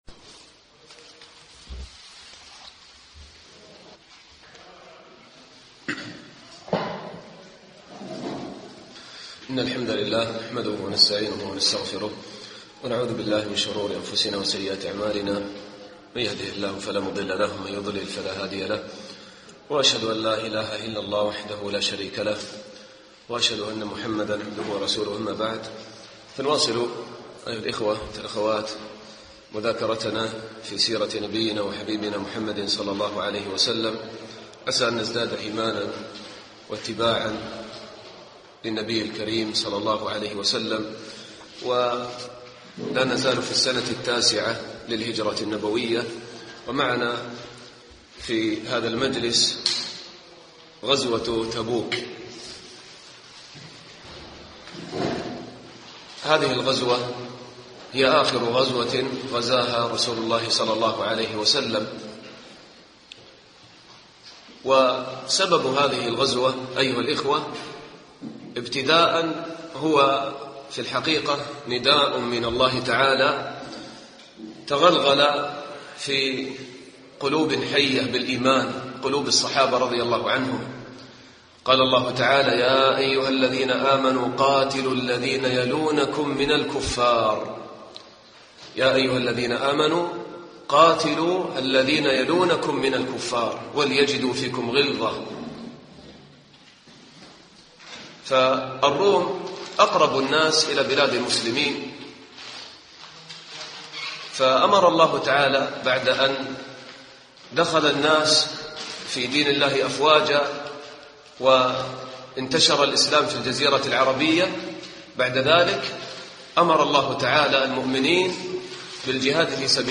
الدرس العشرون